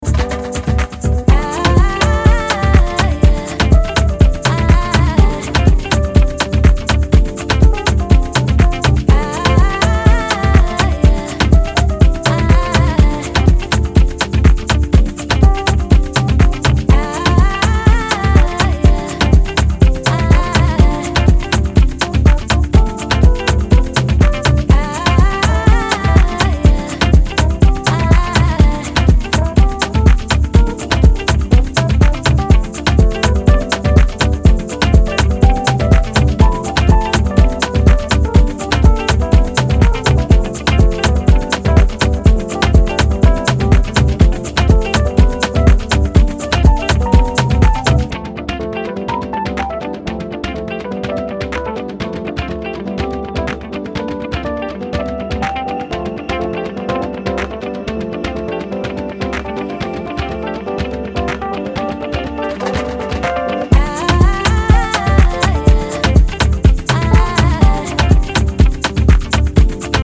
typically deep and soulful cuts.